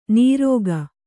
♪ nīrōga